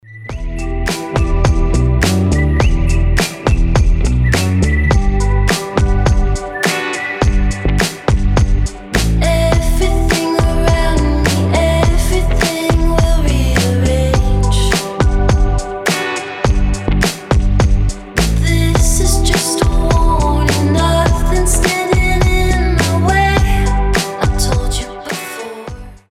• Качество: 320, Stereo
красивый женский голос
indie pop
alternative